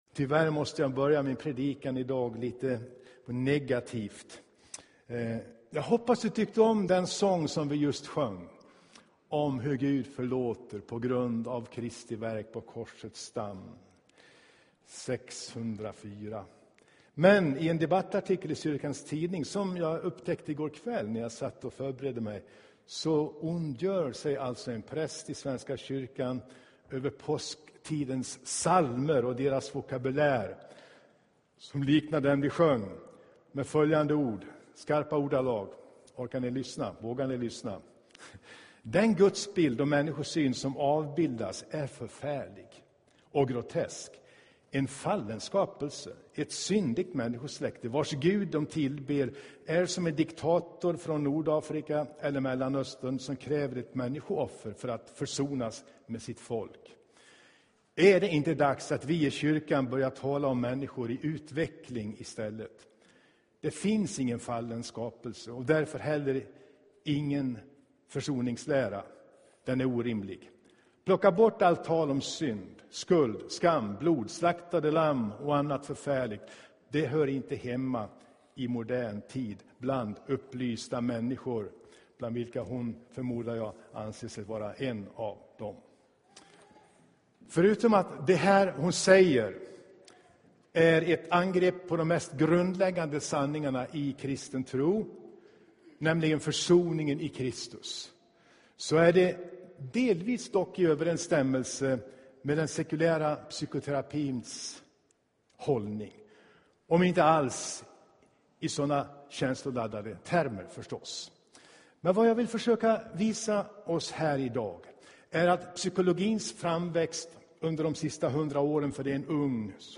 Inspelad i Tabernaklet i Göteborg 2011-04-10.